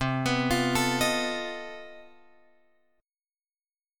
C Minor Major 13th